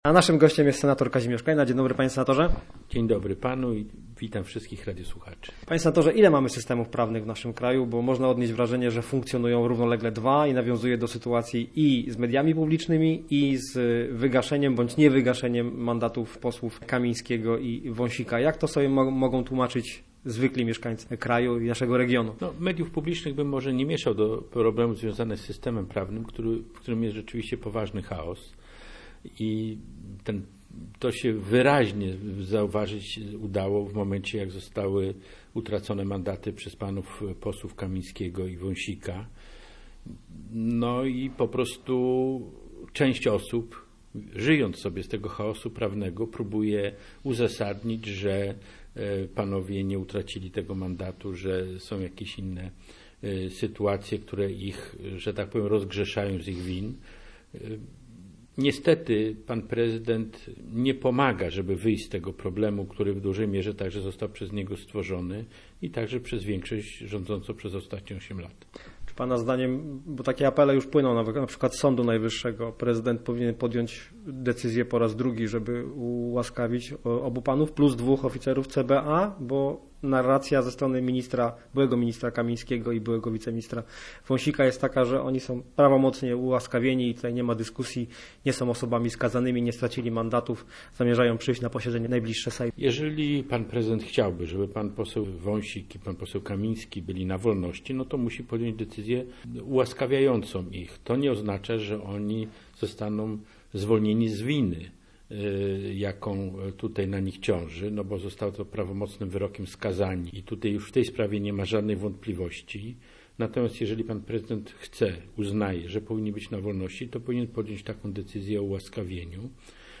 Senator Kazimierz Kleina był gościem miejskiego programu Radia Gdańsk Studio Słupsk 102 FM. https